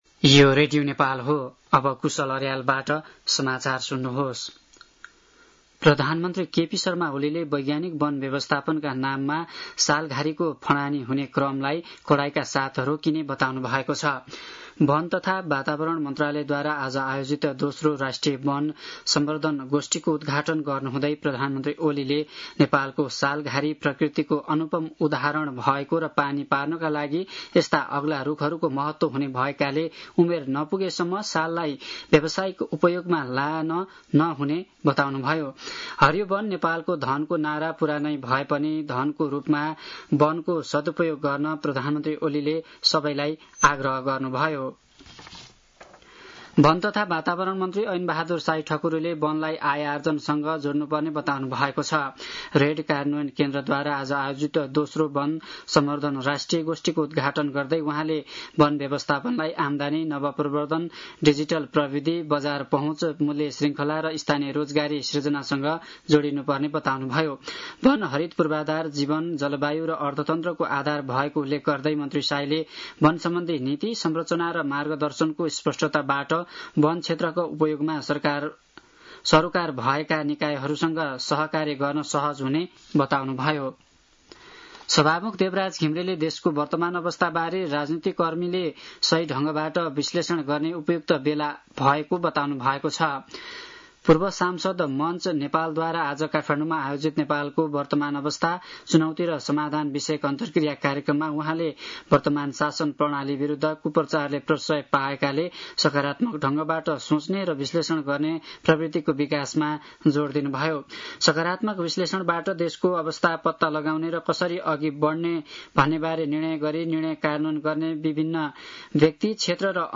साँझ ५ बजेको नेपाली समाचार : २७ वैशाख , २०८२